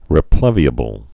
(rĭ-plĕvē-ə-bəl)